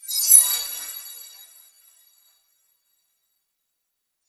Added music and sfx.